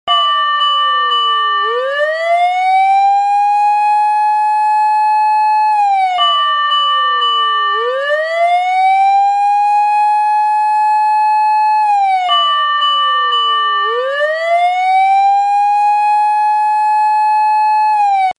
消防サイレンmp3
sairen.mp3